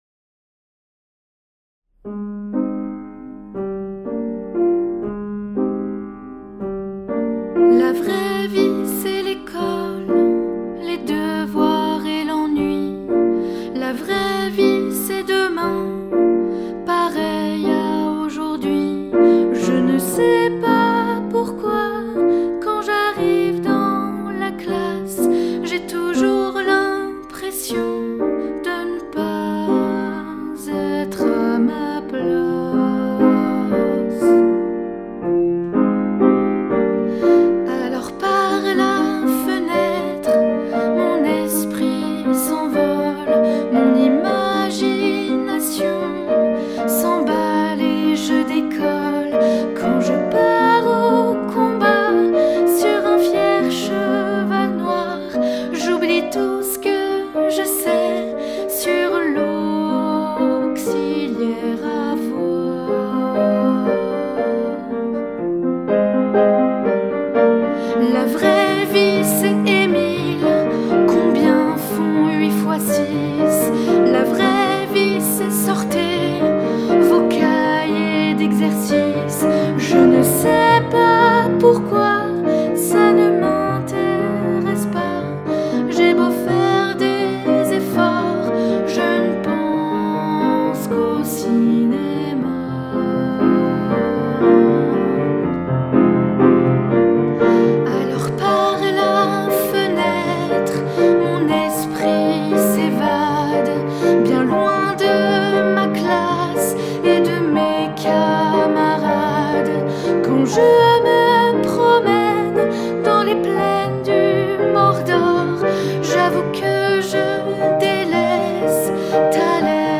Genre :  ChansonComptine
Effectif :  UnissonVoix égales
Audio voix et piano